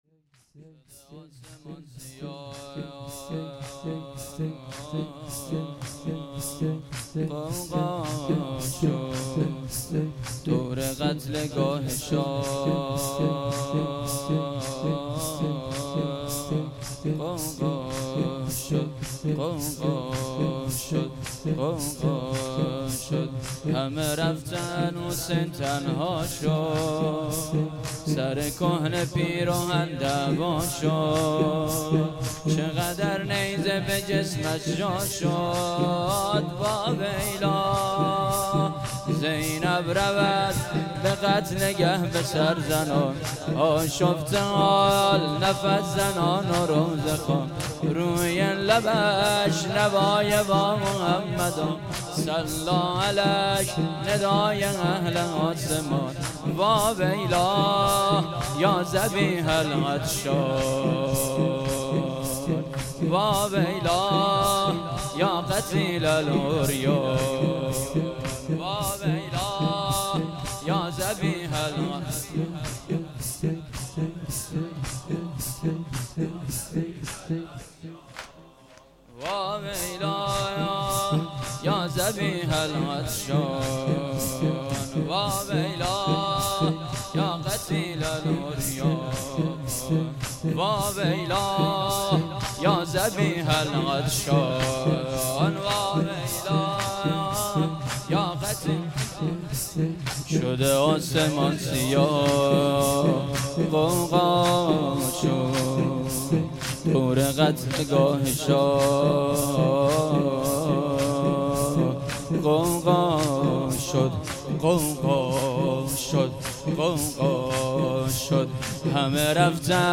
شب دهم محرم1398